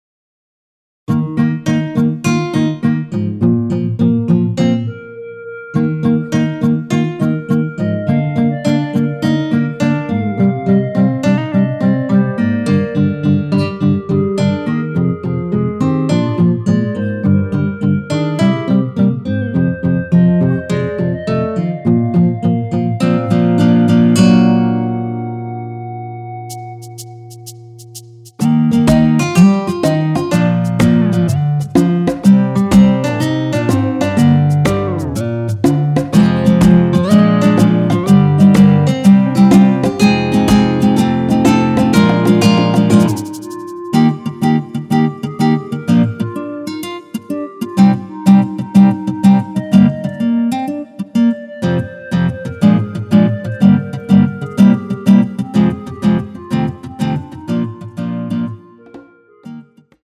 전주 없이 시작 하는곡이라 노래 하시기 편하게 전주 2마디 많들어 놓았습니다.(미리듣기 확인)
원키에서(-1)내린 멜로디 포함된 MR입니다.
Eb
앞부분30초, 뒷부분30초씩 편집해서 올려 드리고 있습니다.